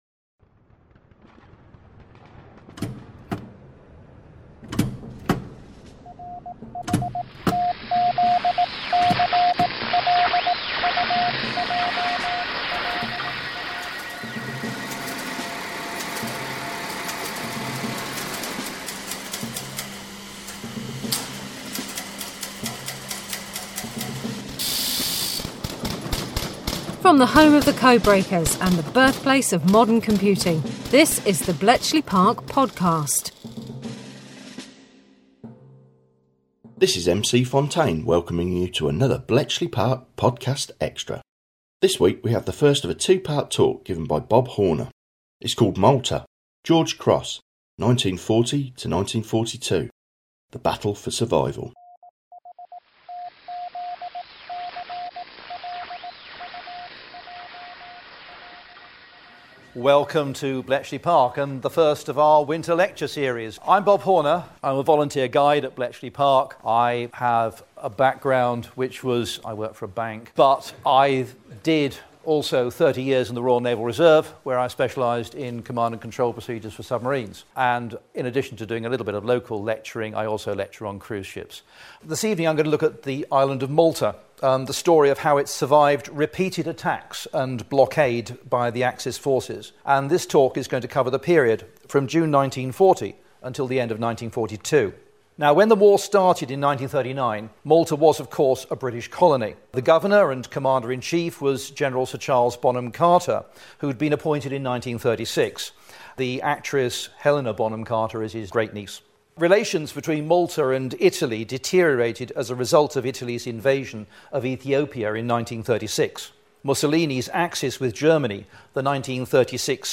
This week we have the first of a two part talk, as part of Bletchley Park Winter Lecture Series